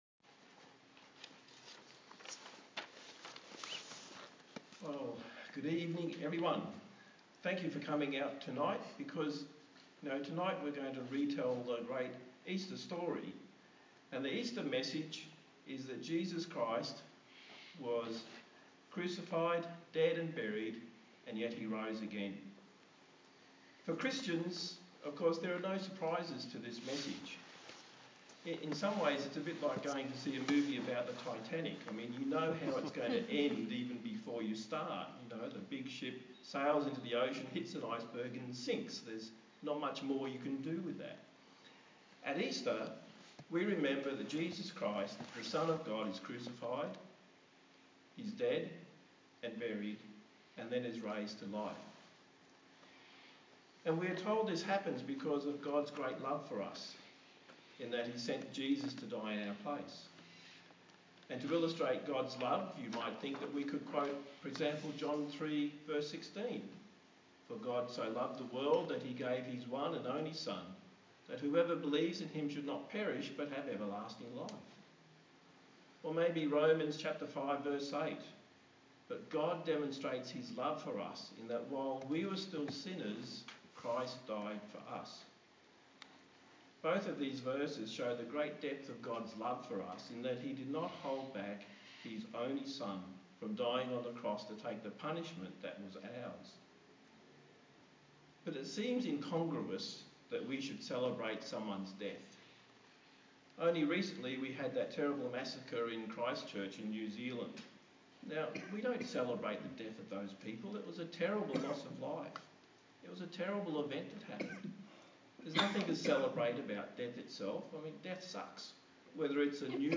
An Easter evening sermon